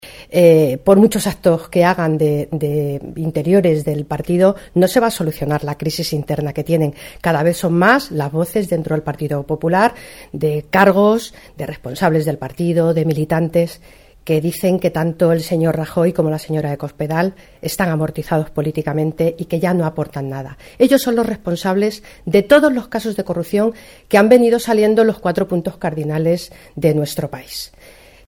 La diputada nacional del PSOE, Guadalupe Martín, asegura que "por muchos actos de partido que hagan no van a poder parar la grave crisis interna que atenaza a los 'populares'"
Cortes de audio de la rueda de prensa